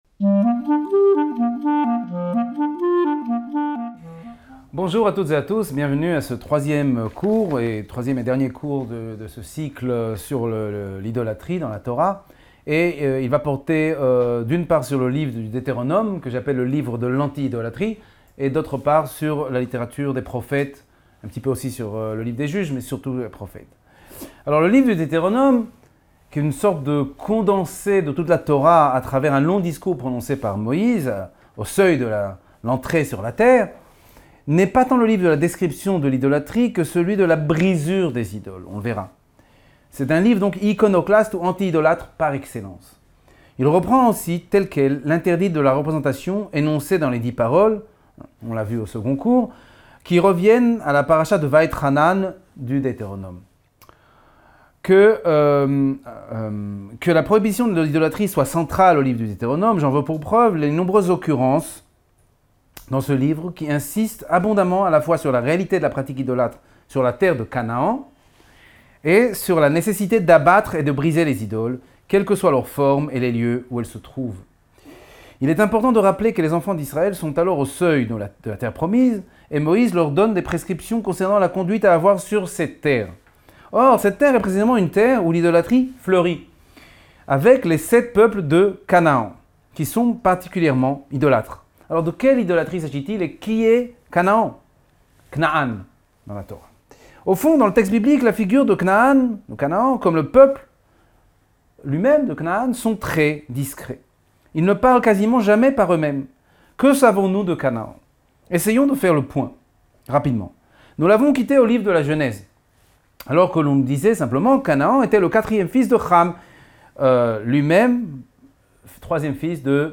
cours Sommes-nous encore idolâtres ?